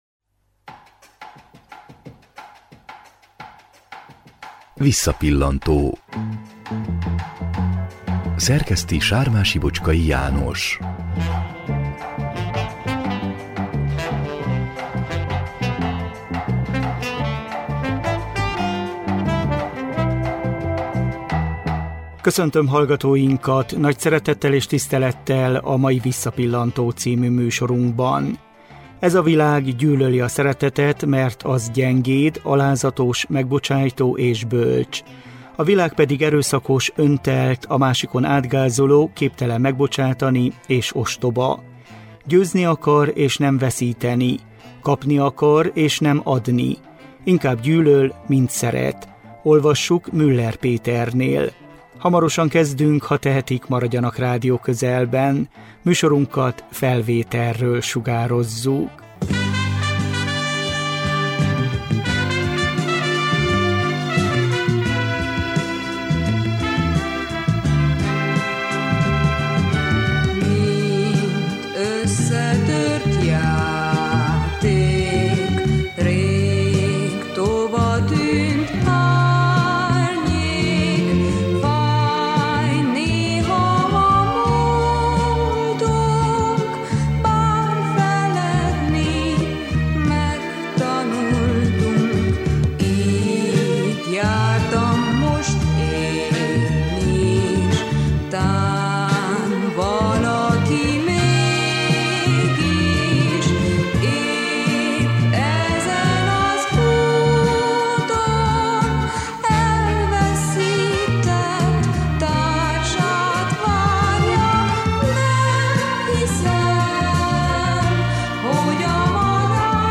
De hogy indult ez a sikertörténet? Egy 2009-ben készült összeállítást forgatunk le.